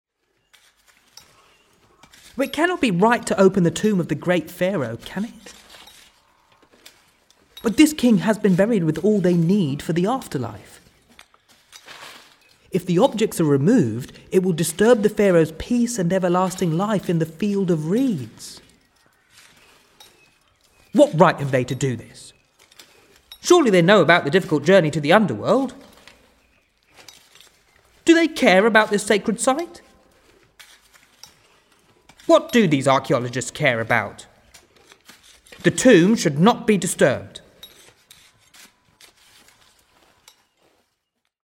Resource 12: Audio - the speech of the Egyptian boy